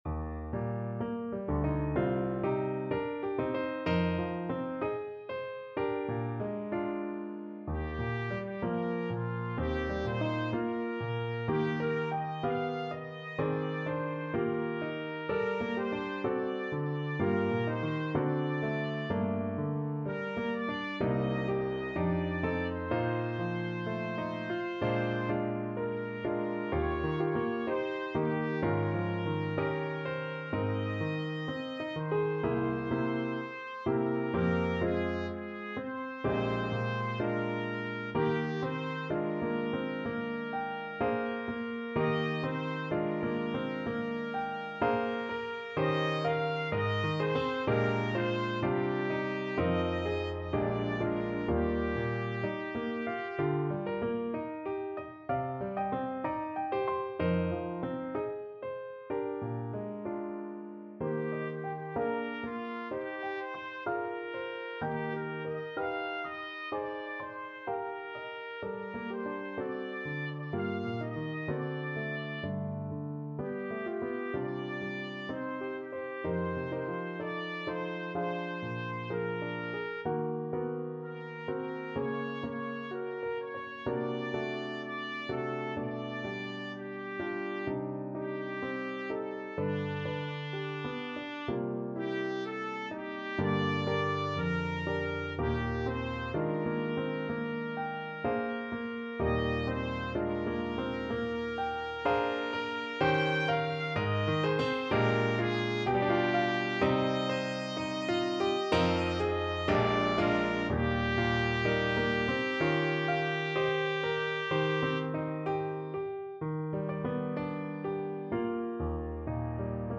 4/4 (View more 4/4 Music)
Classical (View more Classical Trumpet Music)